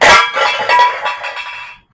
trap_tripwire_cans_0.ogg